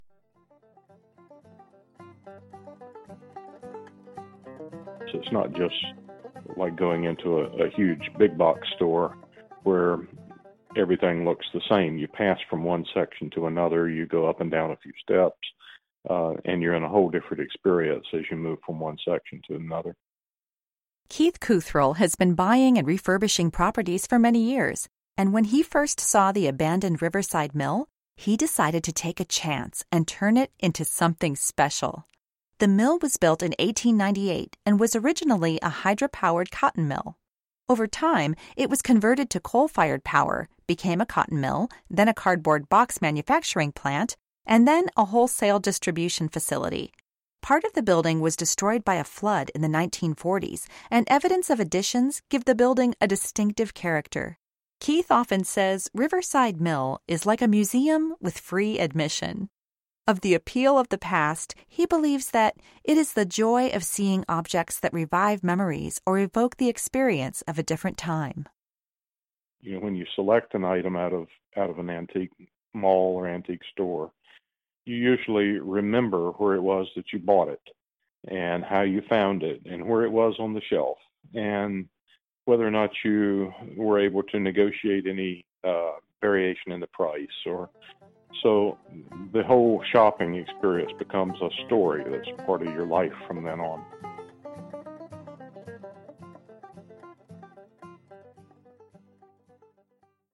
Riverside Mill - Audio Tour